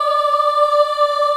Index of /90_sSampleCDs/USB Soundscan vol.28 - Choir Acoustic & Synth [AKAI] 1CD/Partition A/01-CHILD AHF
D4 CHIL AH-R.wav